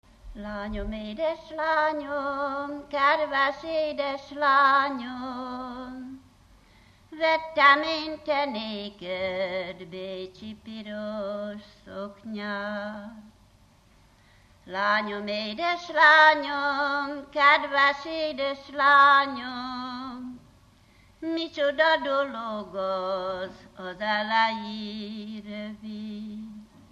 Dunántúl - Somogy vm. - Szenyér
ének
Műfaj: Ballada
Stílus: 7. Régies kisambitusú dallamok
Szótagszám: 6.6.6.6
Kadencia: 4 (b3) 1 1